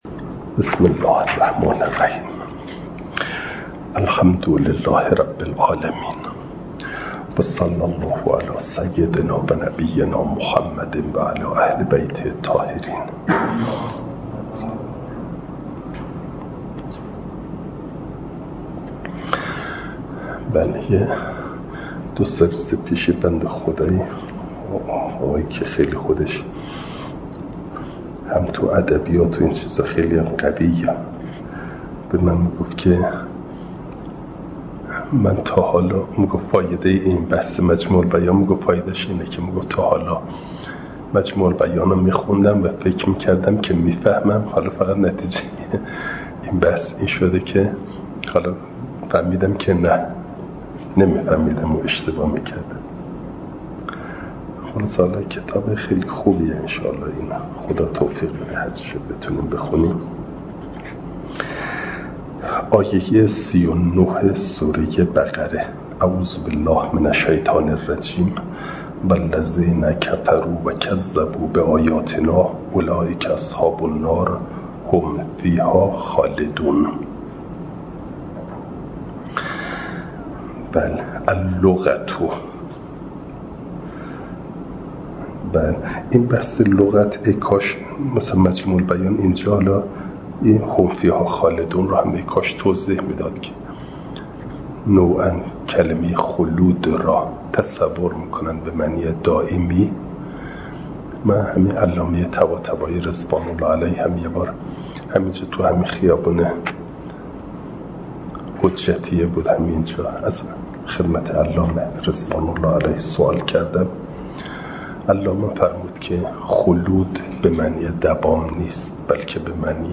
فایل صوتی جلسه پنجاه و دوم درس تفسیر مجمع البیان